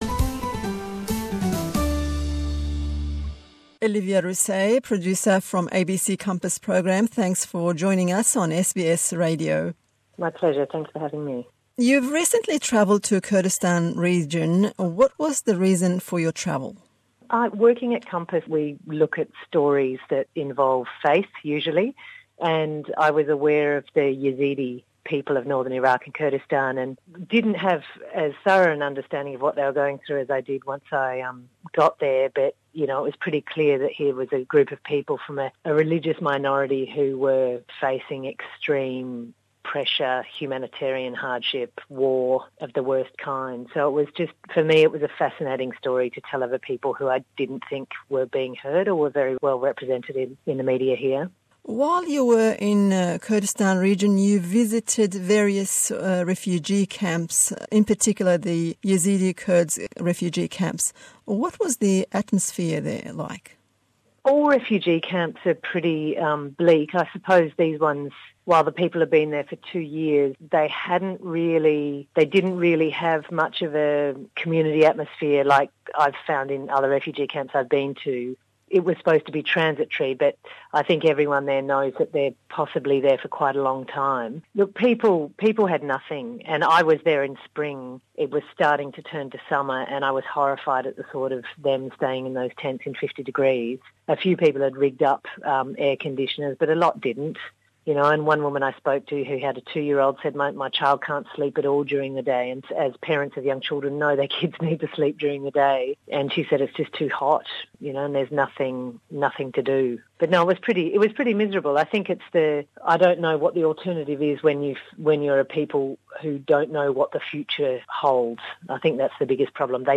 Hevpeyvîn bi zimanî Îngilîziye.